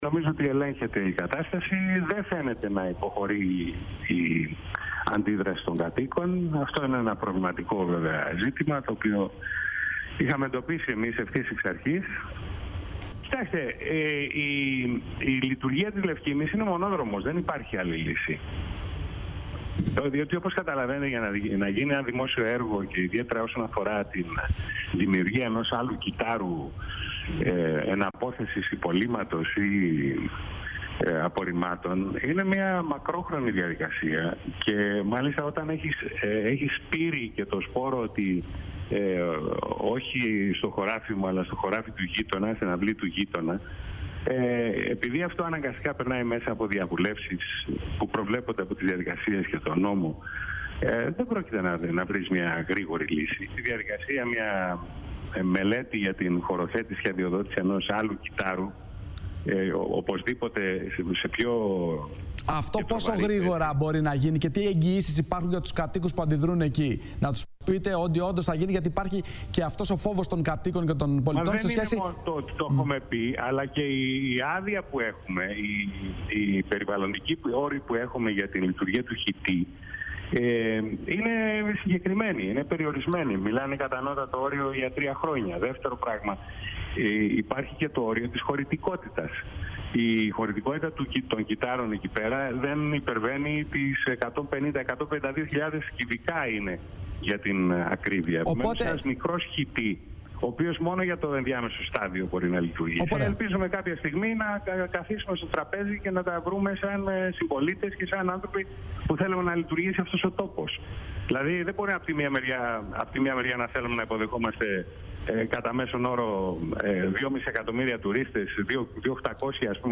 Μιλώντας στην εκπομπή της ΕΡΤ1 « Πρώτη είδηση» ο δήμαρχος Κέρκυρας επανέλαβε ότι η Λευκίμμη είναι μονόδρομος για την ενδιάμεση διαχείριση. Παράλληλα ο κος Νικολούζος υπογράμμισε ότι η εγκατάσταση της Λευκίμμης μπορεί να λειτουργήσει μόνο προσωρινά και για περιορισμένο χρονικό διάστημα καθώς η χωρικότητα των κύτταρων δεν μπορεί να ξεπεράσει τα 150χιλιάδες κυβικά.